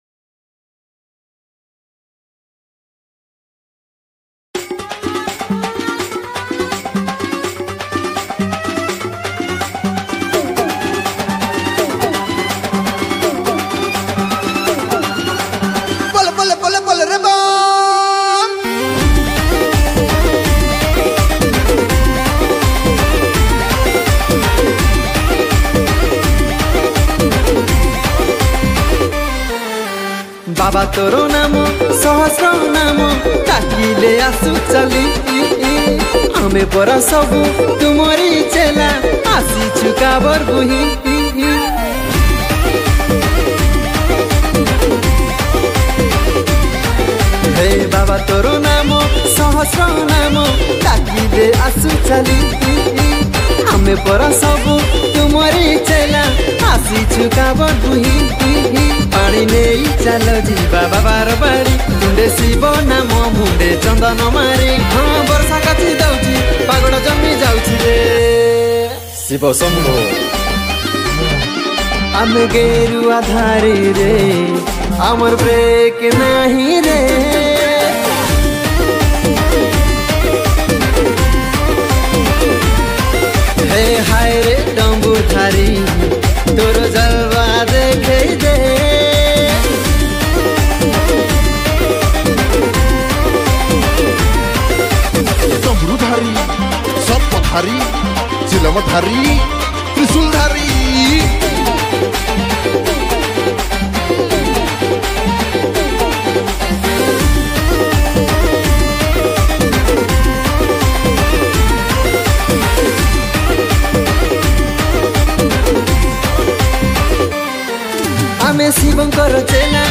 • Category :Bolbum Special Song